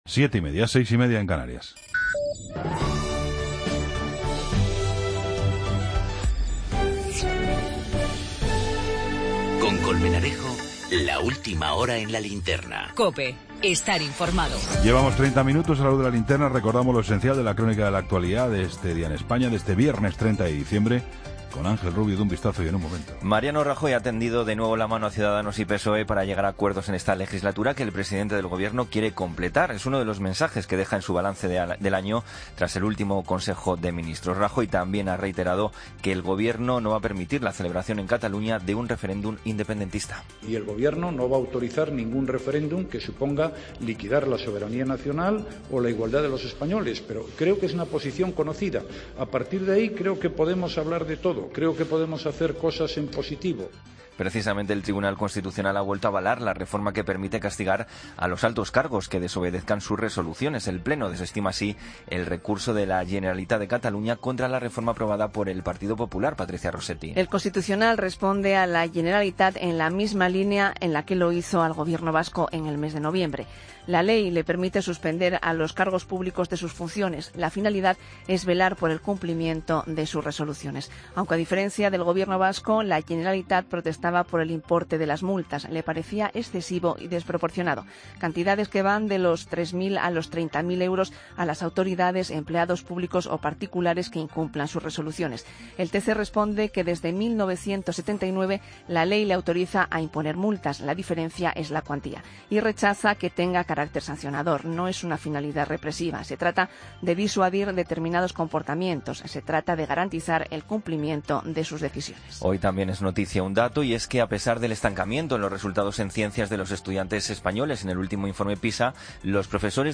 AUDIO: Toda la información con Juan Pablo Colmenarejo. Ronda de corresponsales.